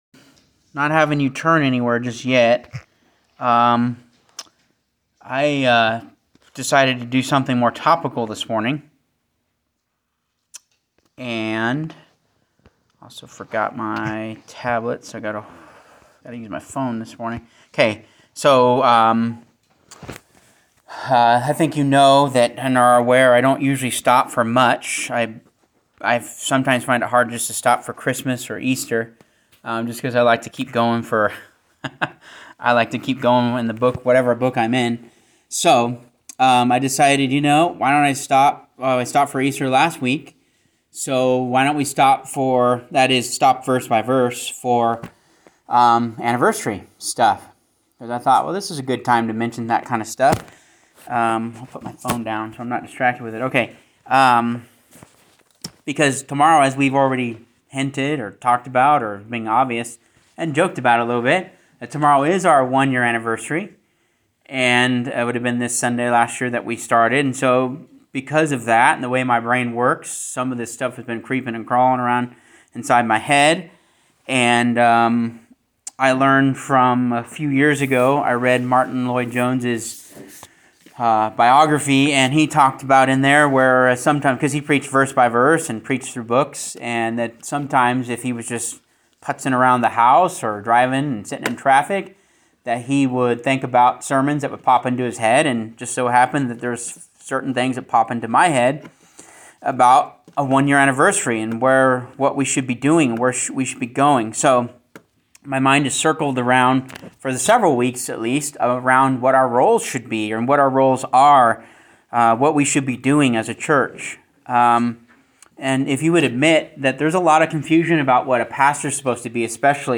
“Anniversary Sermon”